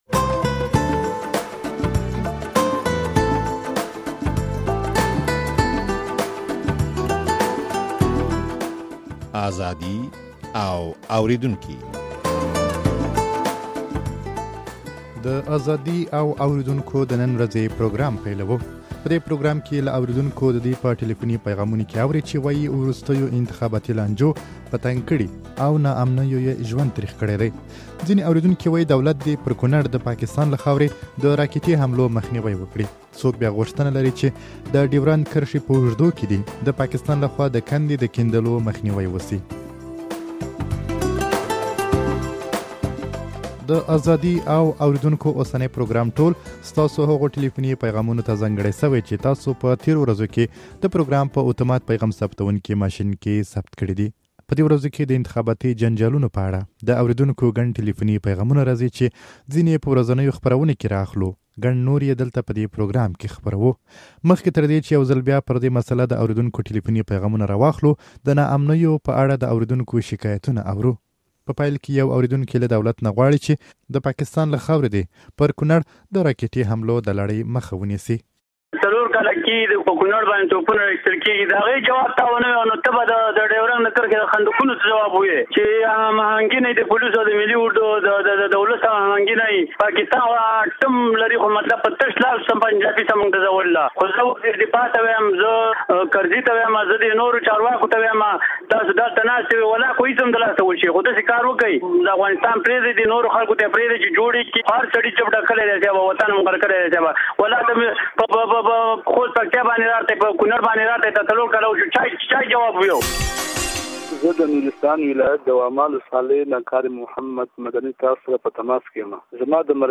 په دې پروګرام کې له اورېدونکو د دوى په ټليفوني پيغامونو کې وارئ چې وايي وروستيو انتخاباتي لانجو په تنګ کړي او ناامنيو يې ژوند تريخ کړى دى. ځينې اورېدونکي وايي دولت دې پر کونړ د پاکستان له خاورې د راکټي حملو مخنيوى وکړي.